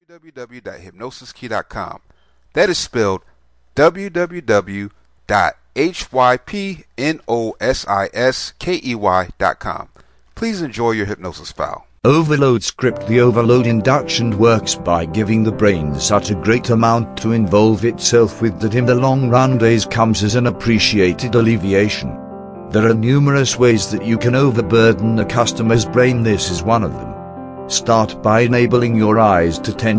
Overload Self Hypnosis Mp3